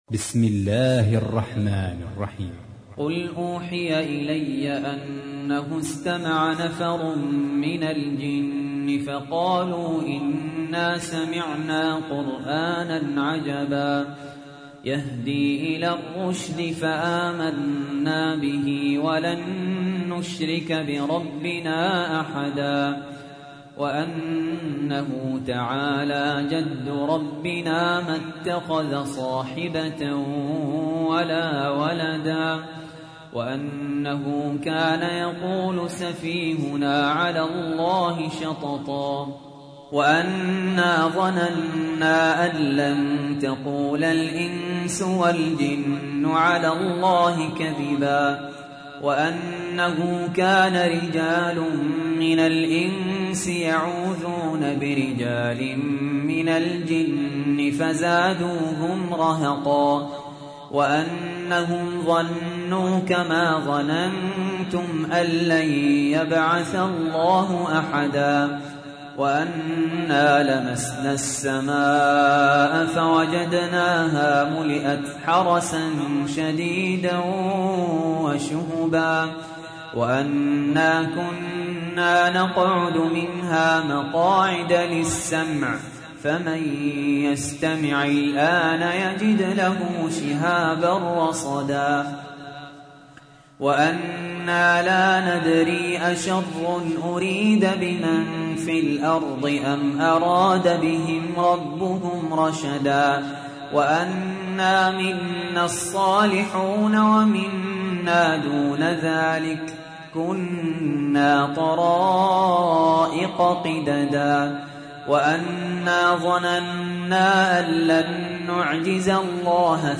تحميل : 72. سورة الجن / القارئ سهل ياسين / القرآن الكريم / موقع يا حسين